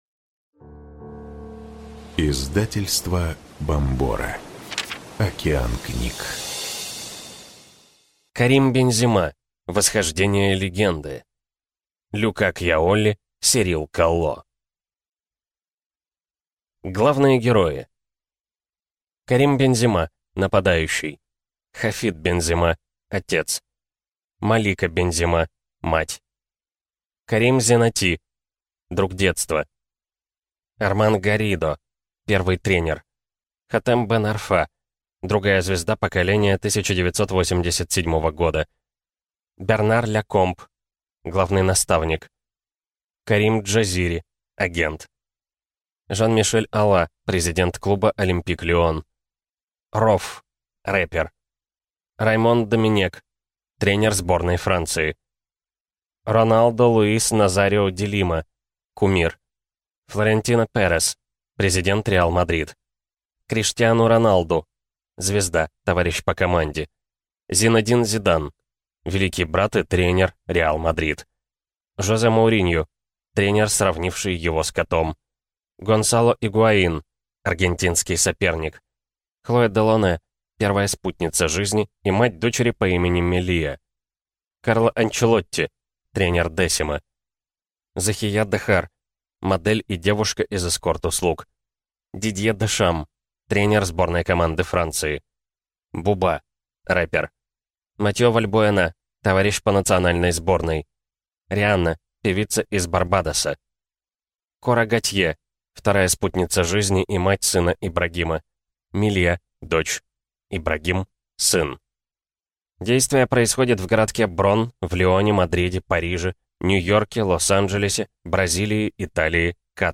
Аудиокнига Карим Бензема. Восхождение легенды | Библиотека аудиокниг